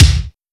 Tuned kick drum samples Free sound effects and audio clips
• Kick Drum D Key 271.wav
Royality free kick sound tuned to the D note. Loudest frequency: 1442Hz
kick-drum-d-key-271-Rhq.wav